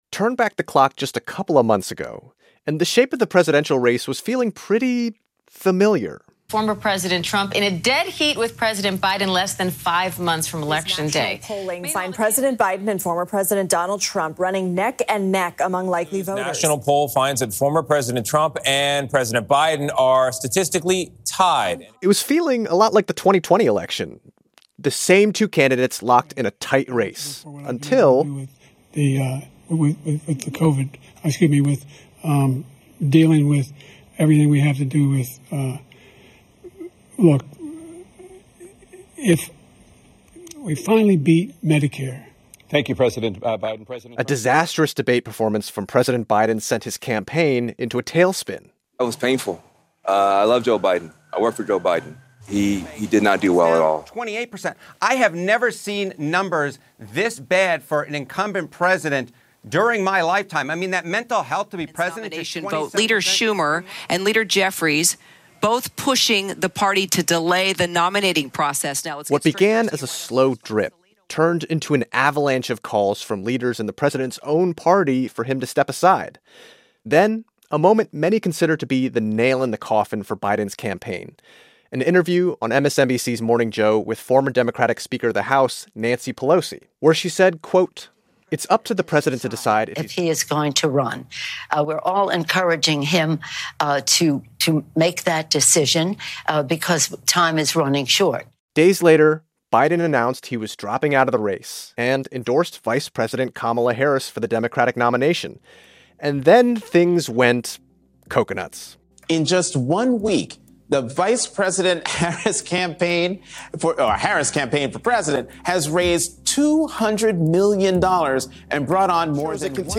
co-host Ari Shapiro